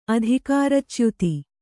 ♪ adhikāracyuti